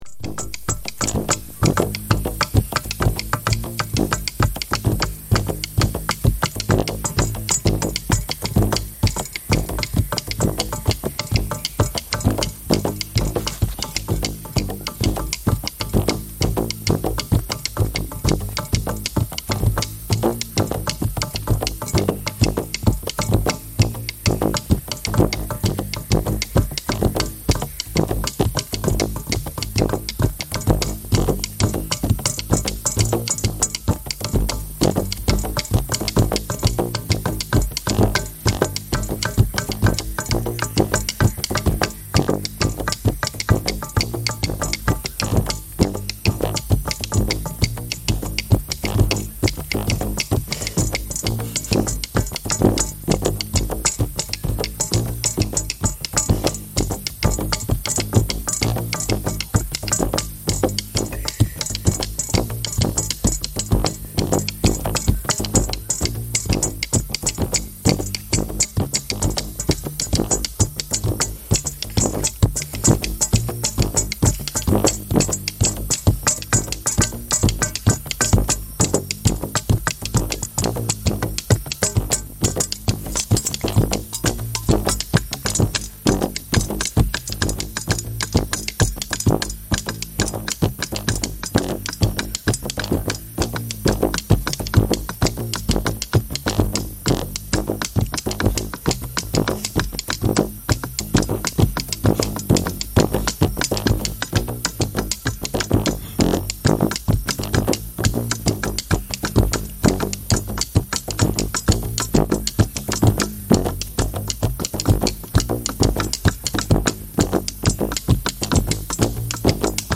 Ngbanda playing the ngbindi (earth bow)